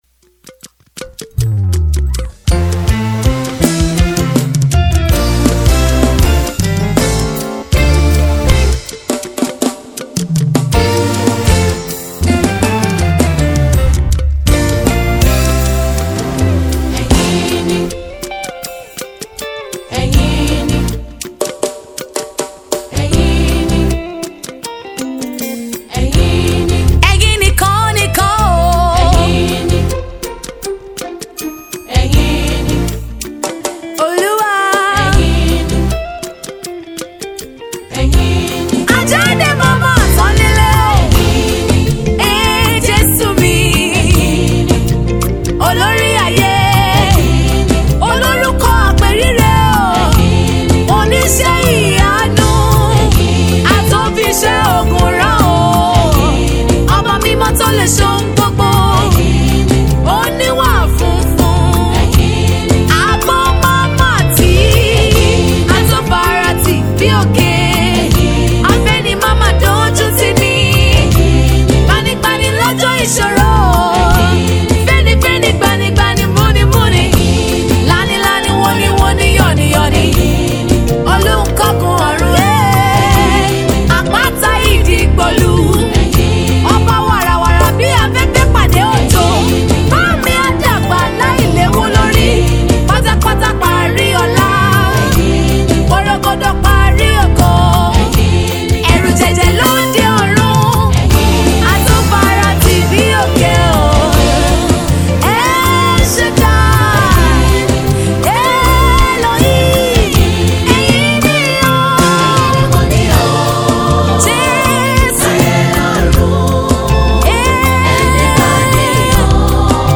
Yoruba Gospel Music